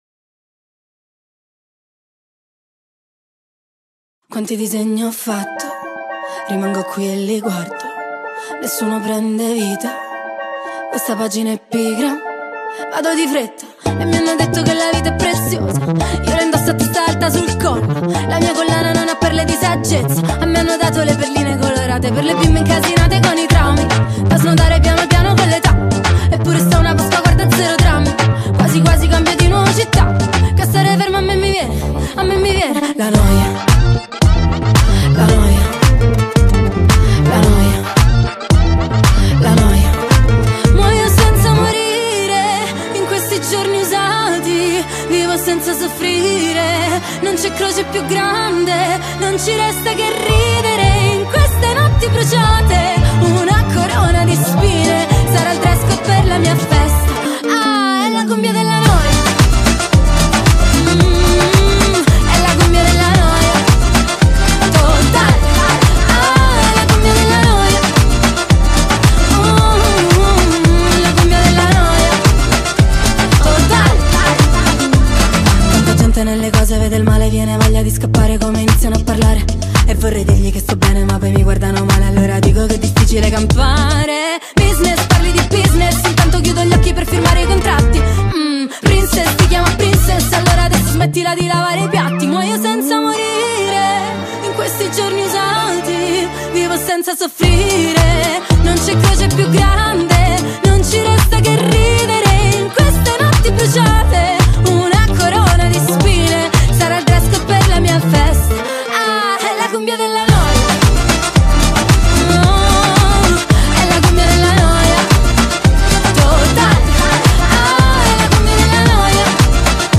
با صدای زن